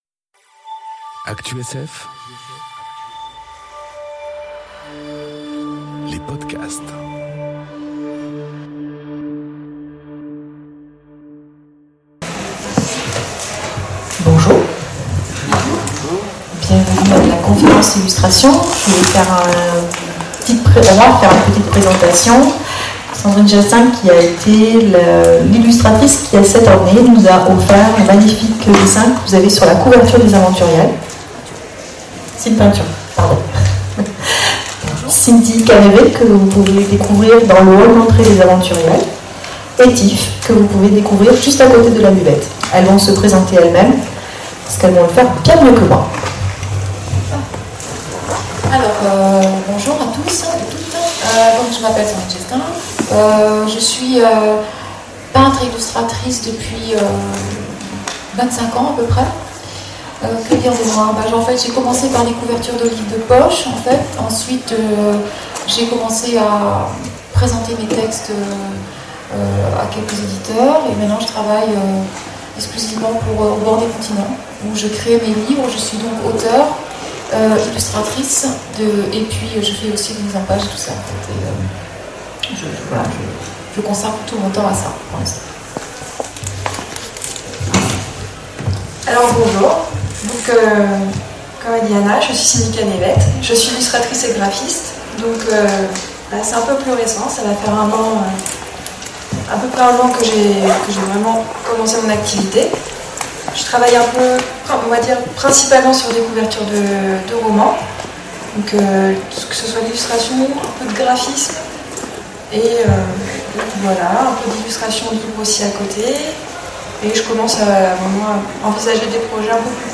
Conférence Illustrer l'imaginaire Table ronde
aux aventuriales de Ménétrol les 29 et 30 septembre 2018.